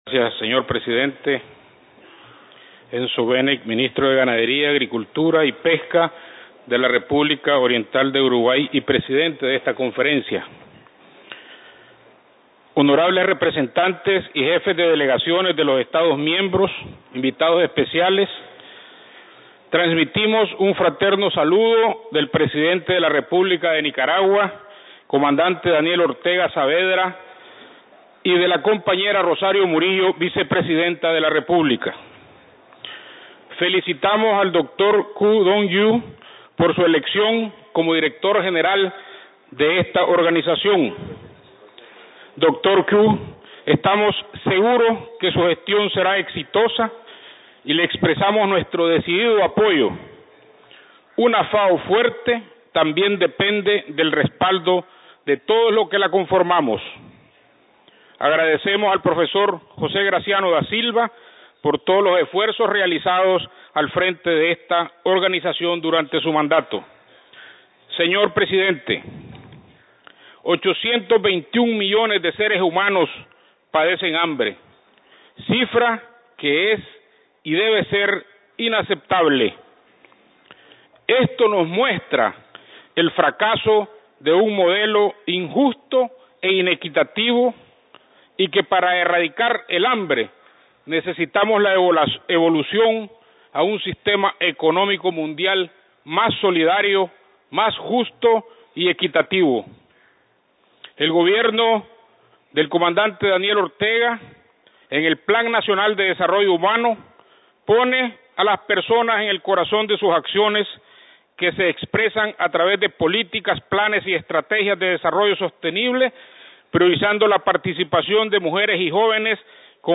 Addresses and Statements
Excmo. Sr. Don Edward Francisco Centeno Gadea Ministro Agropecuario de la República de Nicaragua